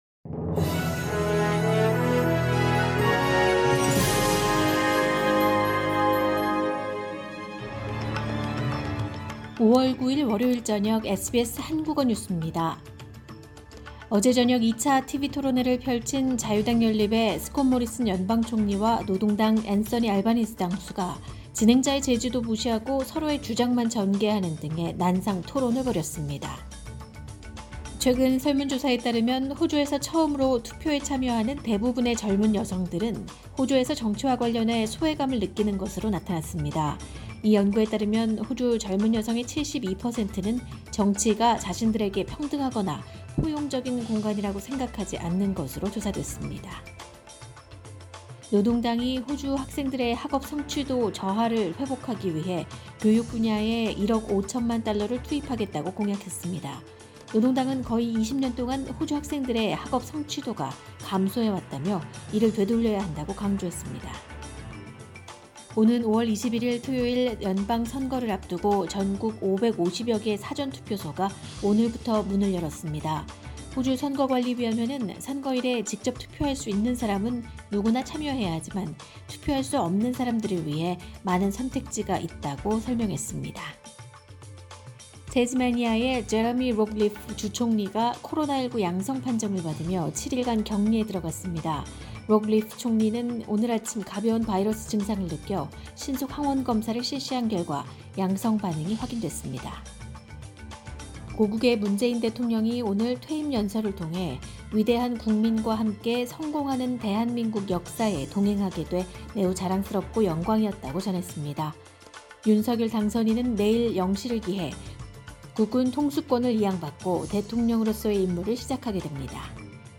SBS 한국어 저녁 뉴스: 2022년 5월 9일 월요일
2022년 5월 9일 월요일 저녁 SBS 한국어 간추린 주요 뉴스입니다.